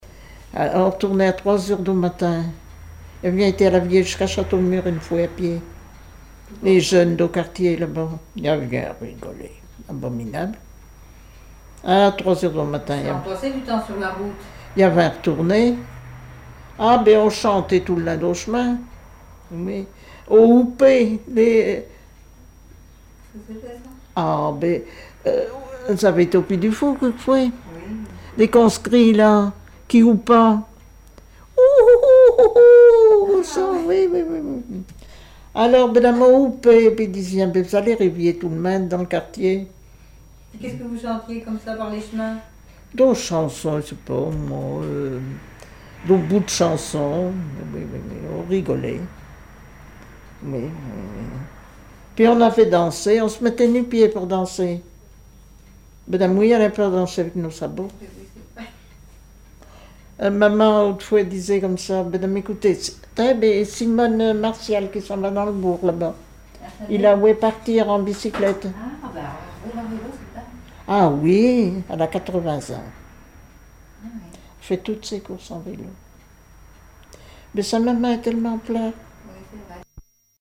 chanteur(s), chant, chanson, chansonnette
Témoignages et chansons
Catégorie Témoignage